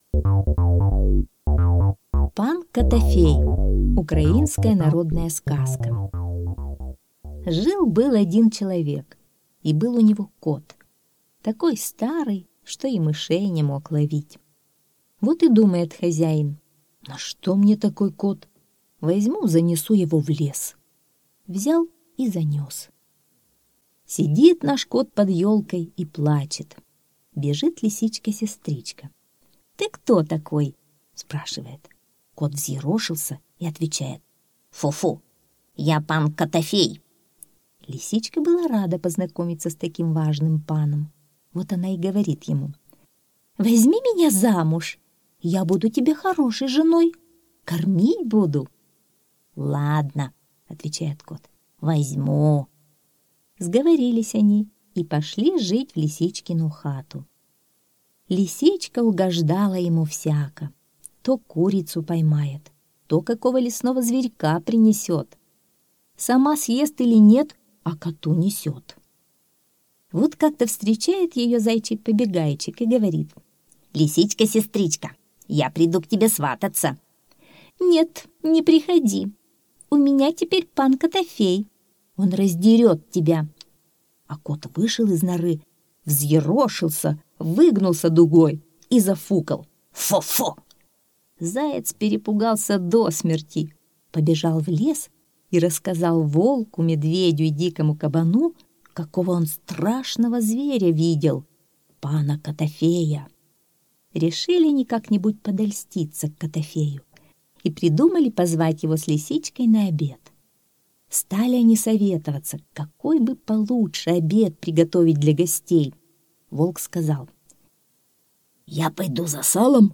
Пан котофей - украинская аудиосказка - слушать онлайн